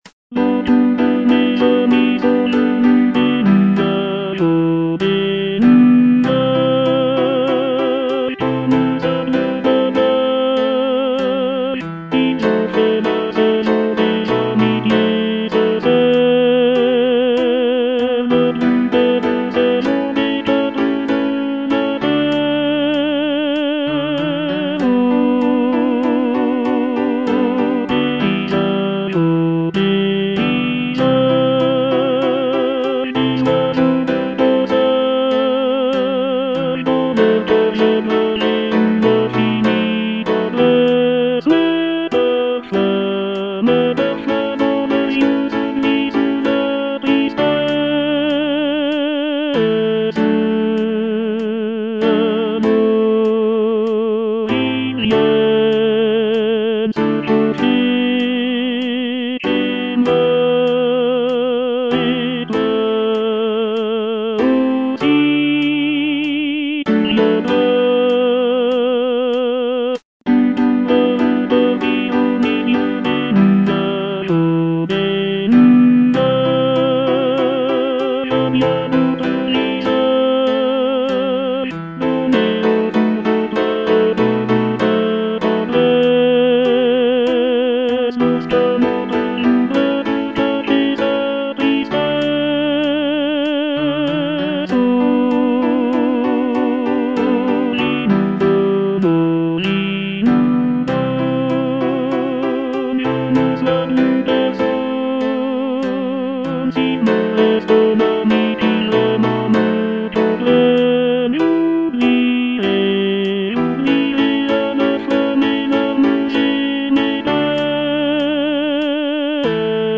Tenor I (Voice with metronome)
piece for choir